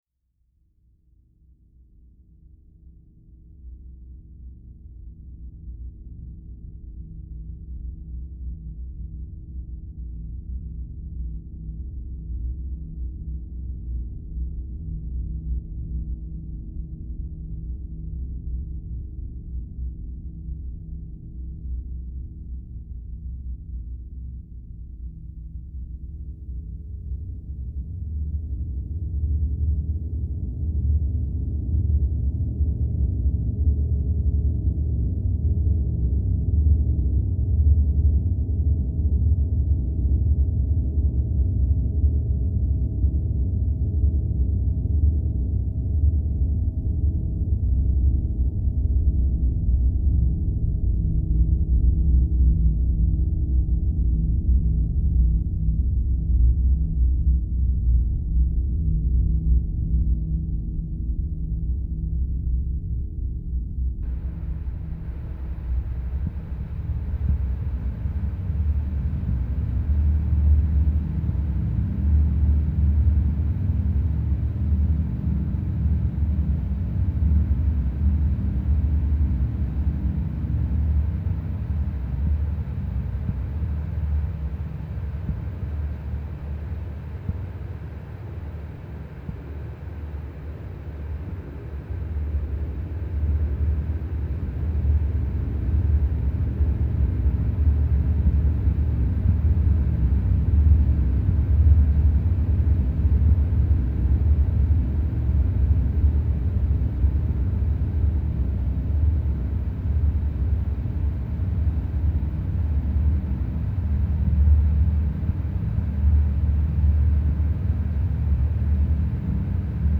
microtunneling.mp3